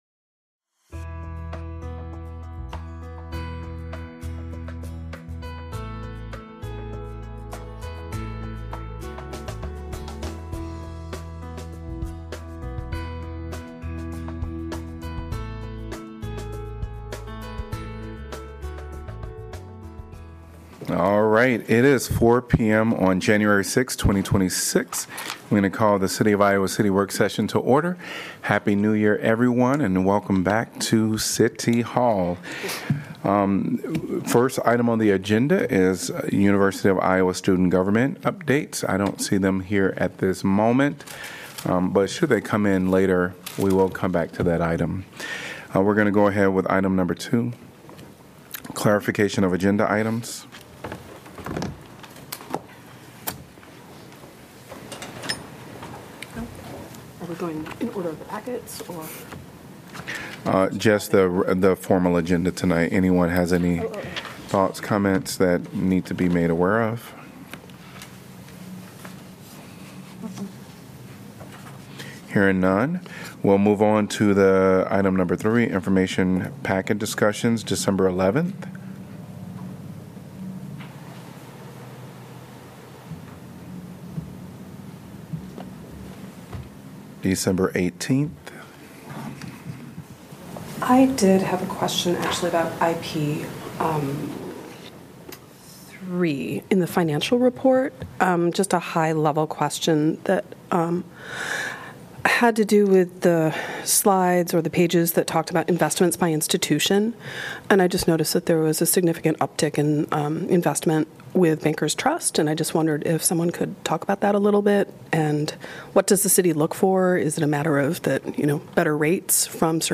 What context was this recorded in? Gavel-to-gavel coverage of the Iowa City City Council meeting, generally scheduled for the first and third Tuesday of each month.